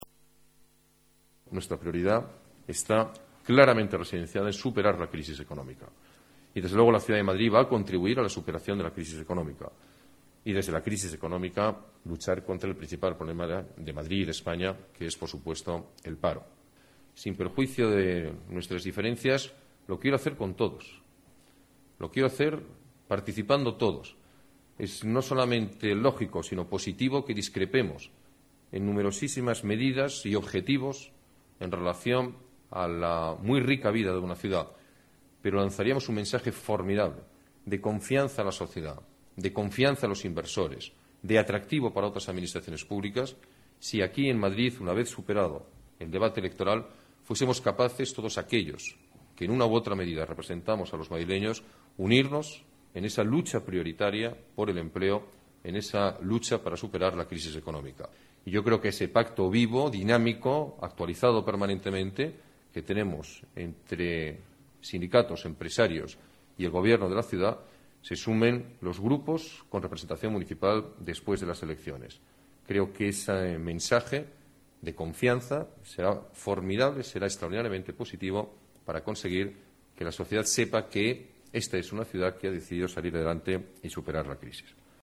Declaraciones alcalde, Alberto Ruiz-Gallardón: valoración elecciones, consenso contra la crisis